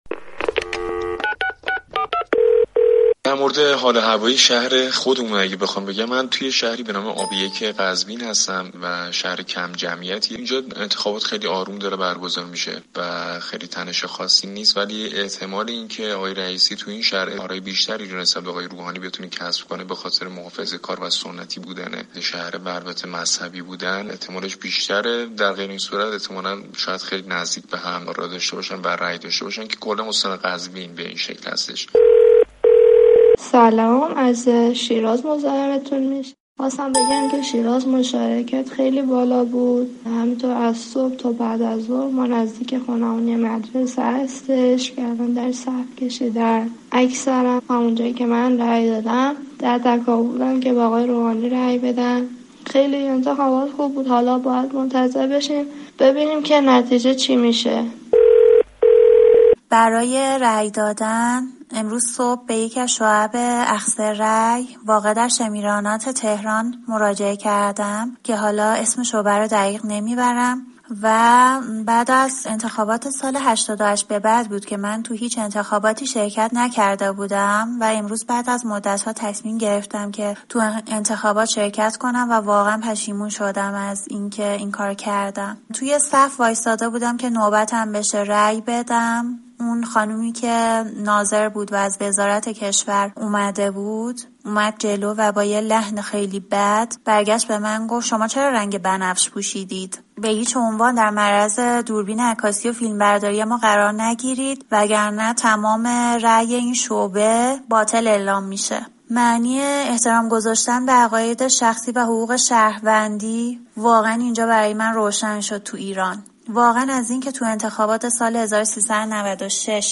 کاربران رادیو فردا در تماس تلفنی از انتخابات می گویند.